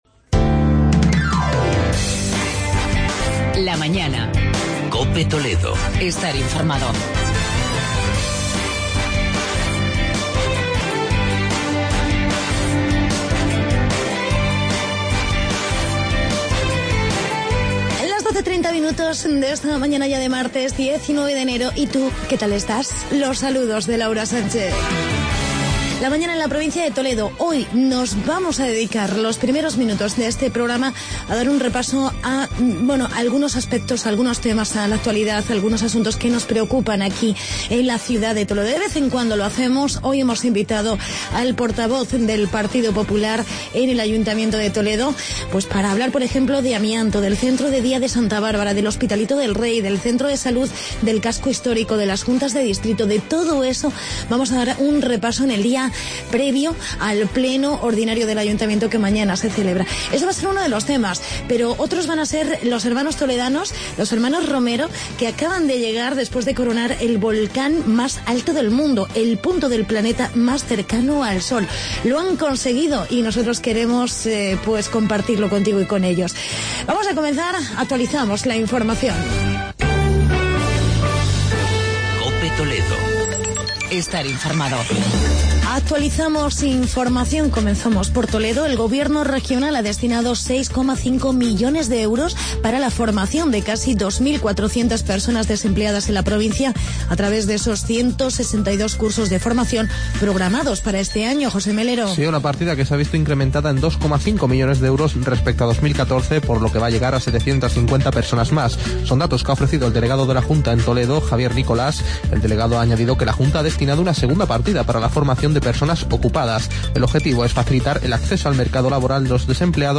Entrevista con Jesús Labrador, portavoz municipal del PP